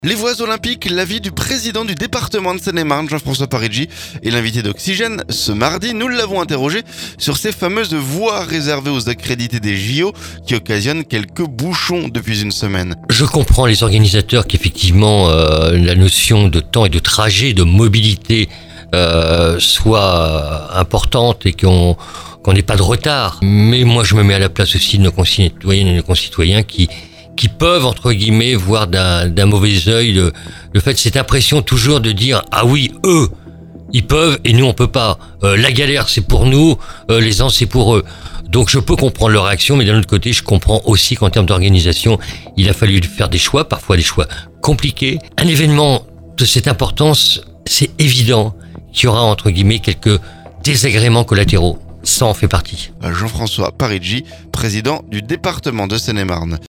Jean-François Parigi st l'invité d'Oxygène ce mardi. Nous l'avons interrogé sur ces fameuses voies réservées aux accrédités des JO...